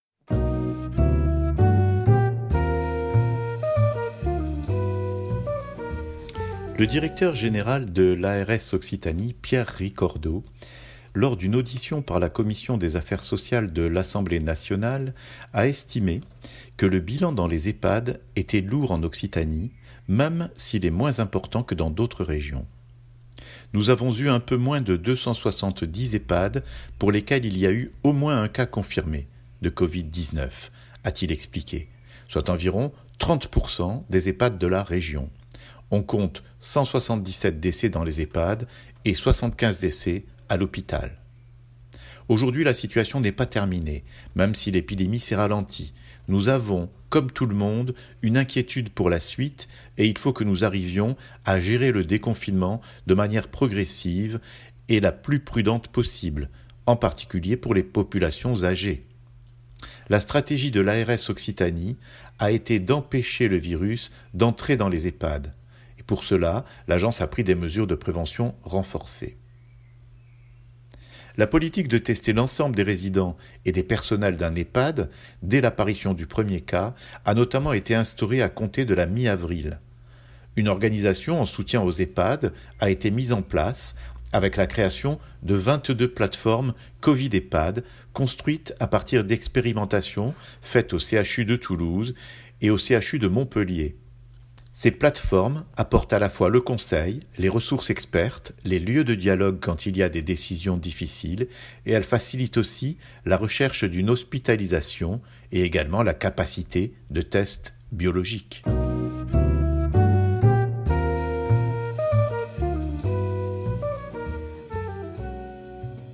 « Nous avons un peu moins de 270 EHPAD pour lesquels il y a eu au moins un cas confirmé de Covid-19 », a estimé Pierre Ricordeau lors d’une audition par la commission des affaires sociales de l’Assemblée nationale à propos du rôle des agences auprès des Ehpad pendant l’épidémie.